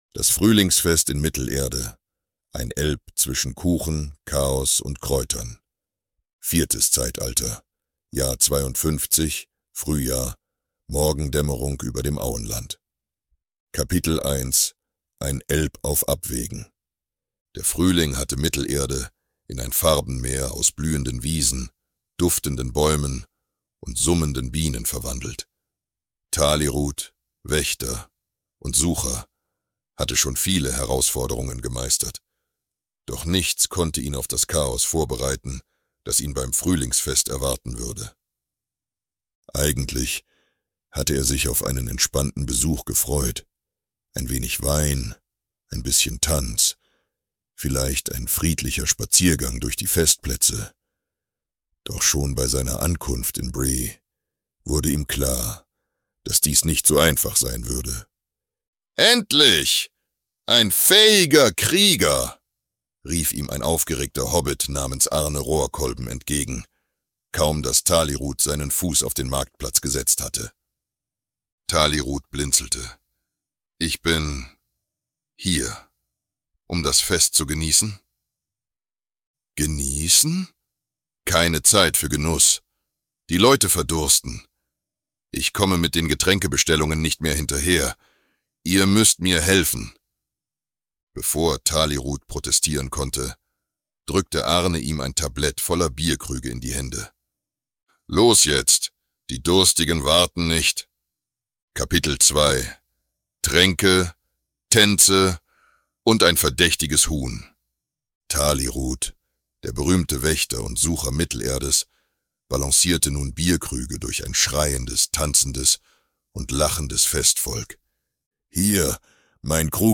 Diese Podcast-Episode ist eine kleine, humorvolle Kurzgeschichte